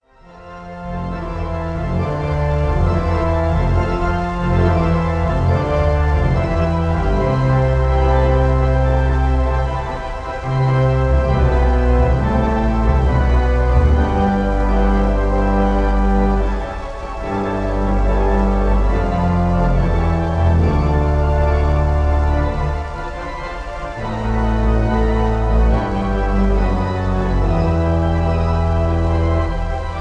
at the organ of St. Marks,
North Audley Street, London